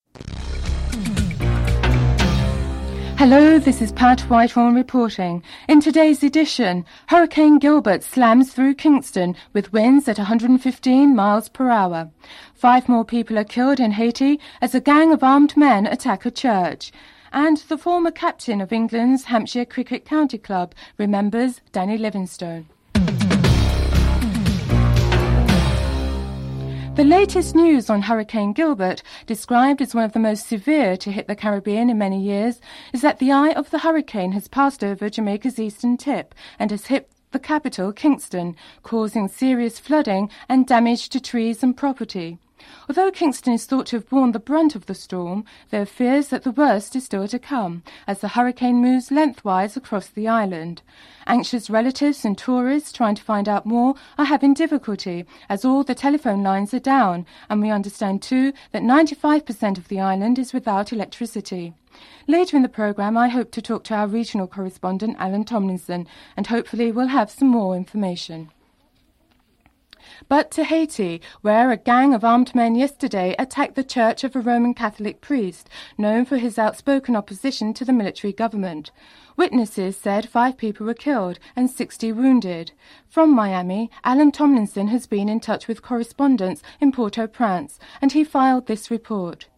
1. Headlines (00:00-00:23)
4. Financial news (03:51-05:27)